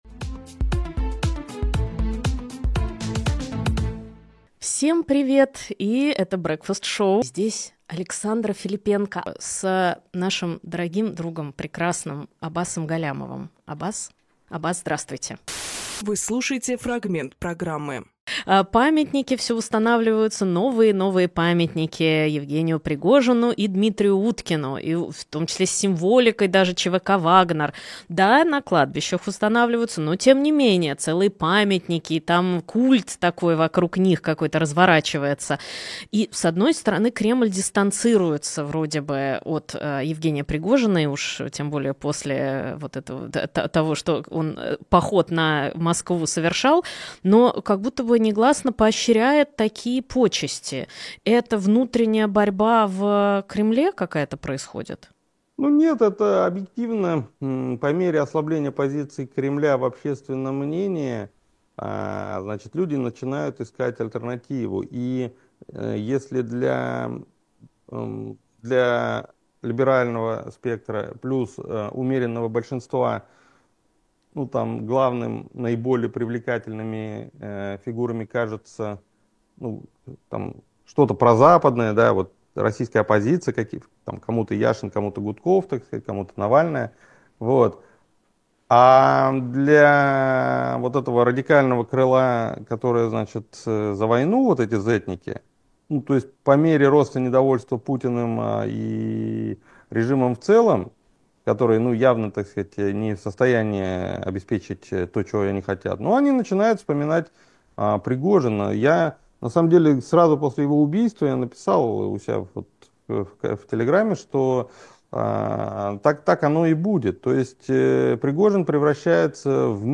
Аббас Галлямовполитолог
Фрагмент эфира от 19.01.25